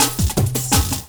35LOOP01SD-R.wav